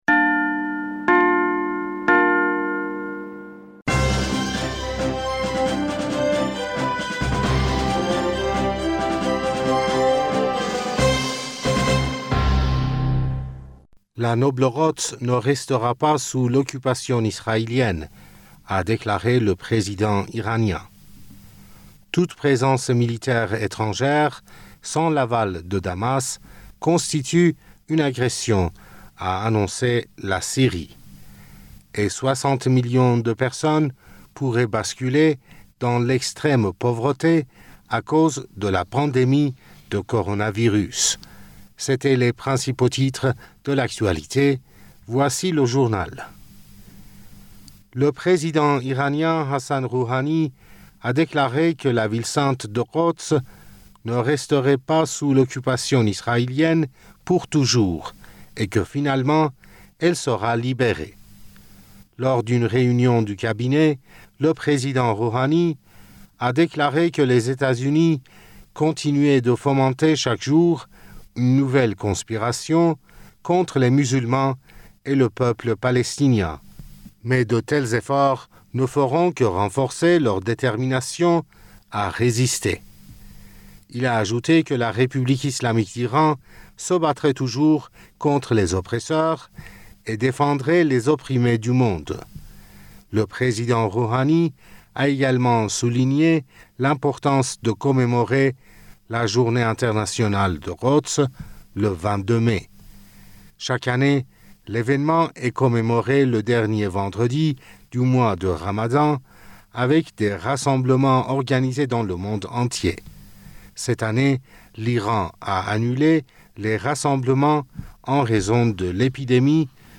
Bulletin d'information du 20 mai 2020